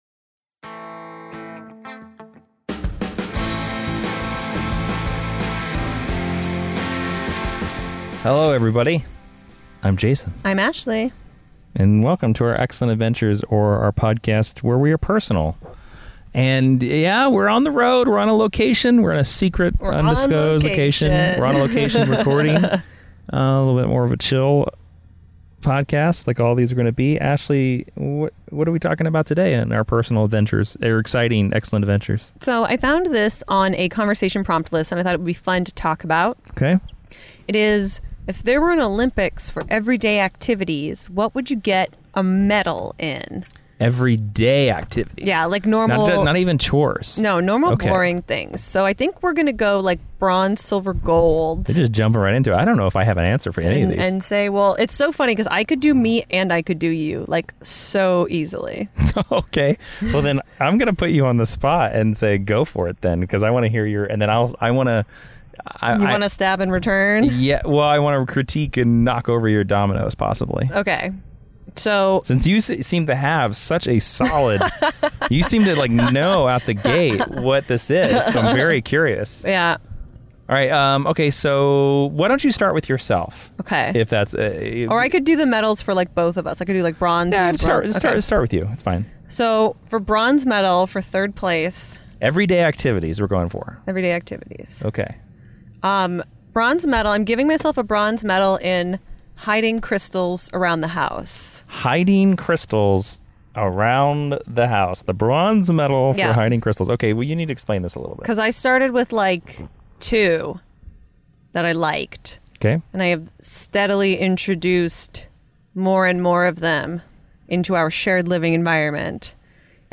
This episode is a "WE'RE IN THE CAR" series! This month we are exploring: If there was an Olympics for Every Day Activities what would we make the podium in?